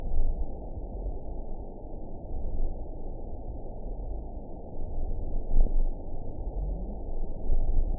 event 920632 date 04/01/24 time 17:13:55 GMT (1 year, 1 month ago) score 5.71 location TSS-AB09 detected by nrw target species NRW annotations +NRW Spectrogram: Frequency (kHz) vs. Time (s) audio not available .wav